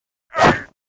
WAV · 77 KB · 單聲道 (1ch)